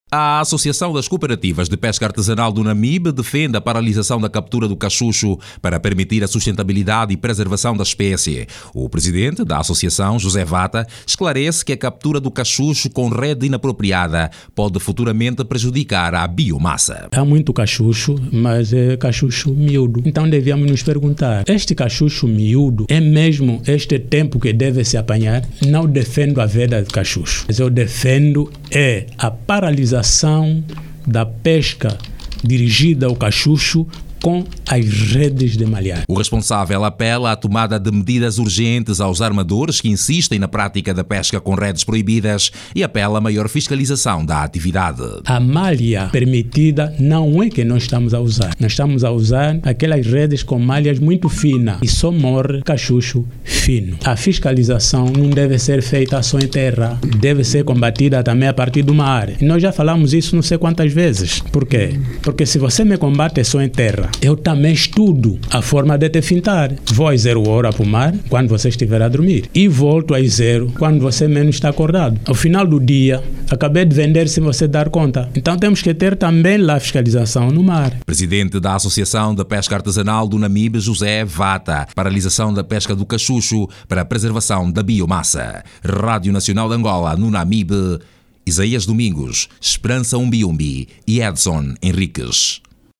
A situação preocupa a Associação das Cooperativas de Pesca Artesanal, que apela às autoridades angolanas para reforçarem a fiscalização da actividade. Jornalista